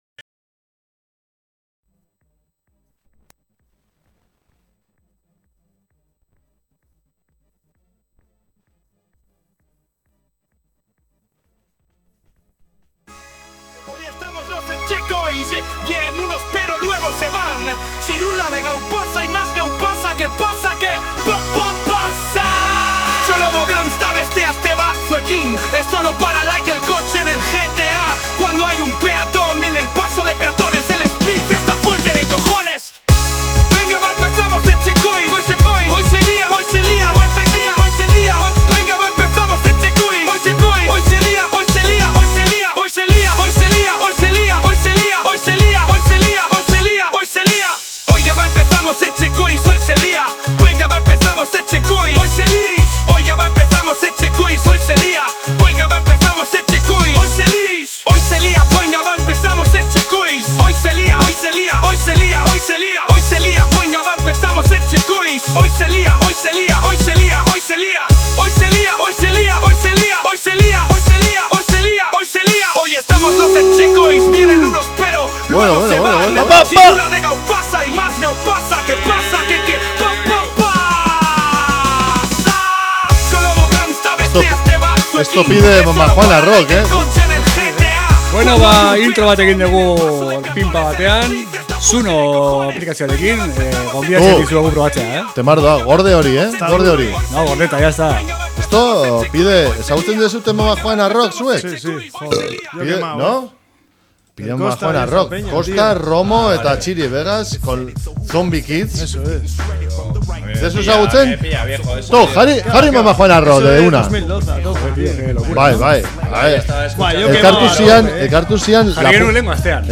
Mundu osoko Rapa, entrebistak zuzenean, Bass doinuak eta txorrada izugarriak izango dituzue entzungai saio honetan.